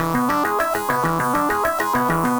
Index of /musicradar/8-bit-bonanza-samples/FM Arp Loops
CS_FMArp A_100-E.wav